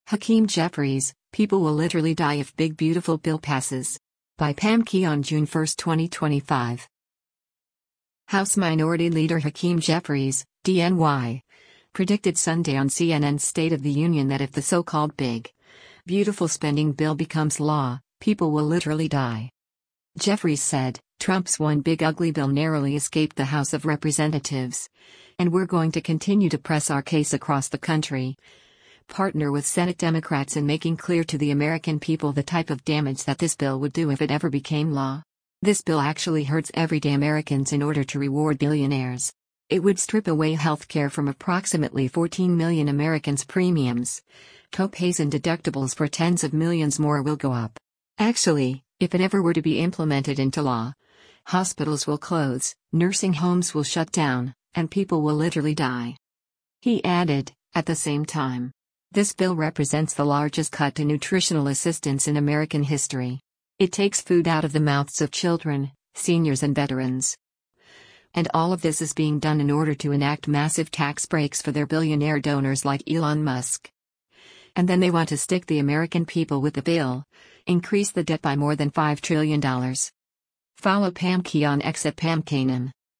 House Minority Leader Hakeem Jeffries (D-NY) predicted Sunday on CNN’s “State of the Union” that if the so-called “big, beautiful” spending bill becomes law, “people will literally die.”